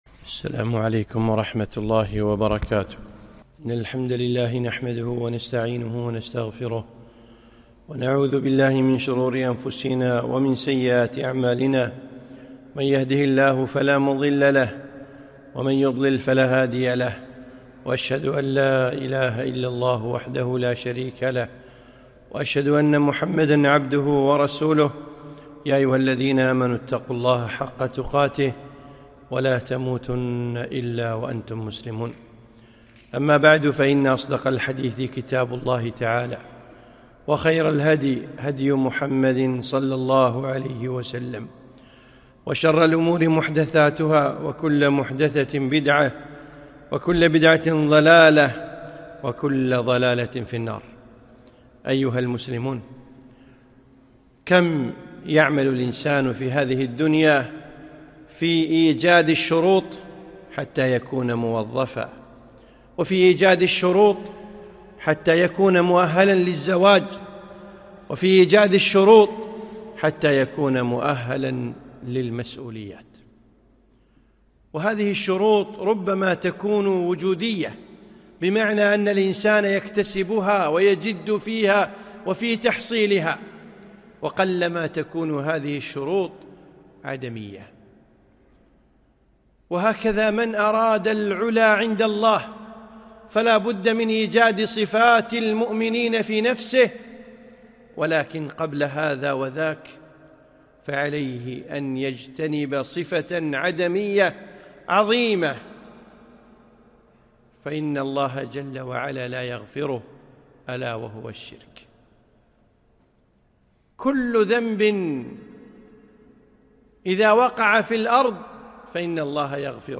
خطبة - ( لا يغفر الله له )